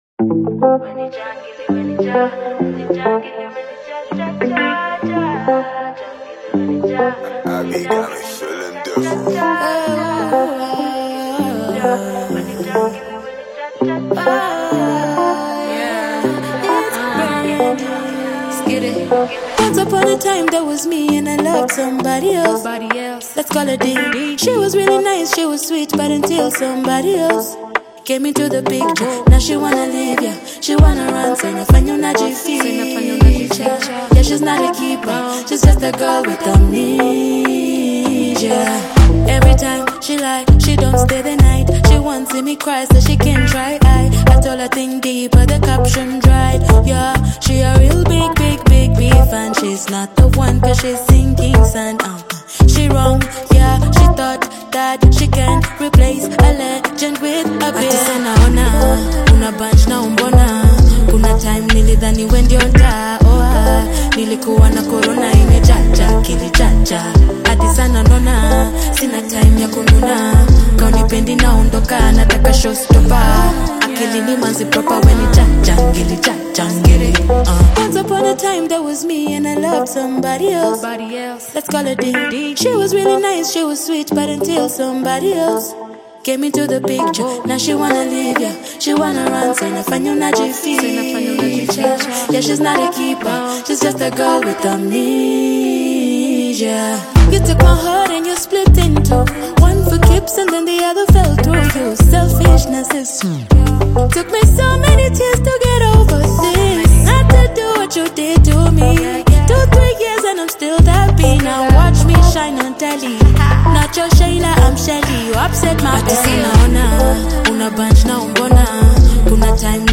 fresh Afro-Urban/Afro-Beat single
Genre: Afrobeat